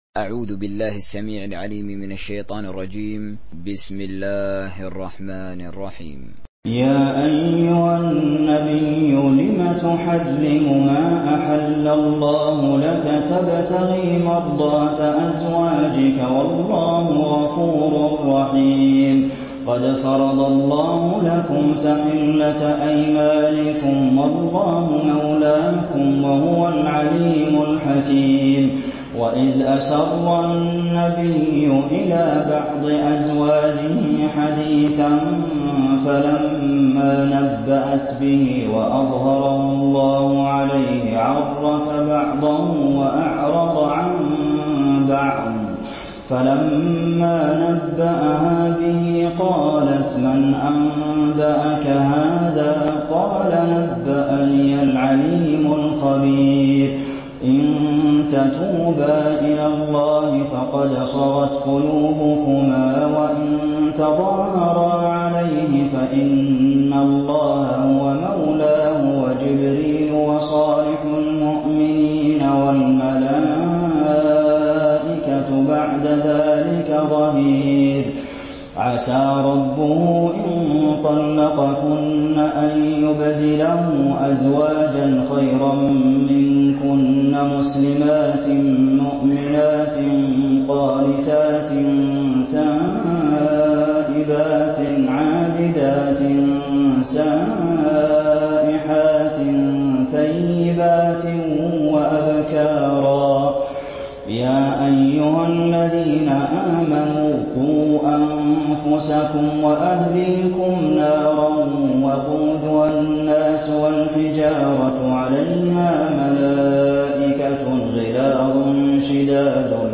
دانلود سوره التحريم حسين آل الشيخ تراويح